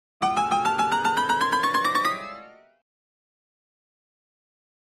Comedy Piano Chromatic Up 4 - Heavy Climbing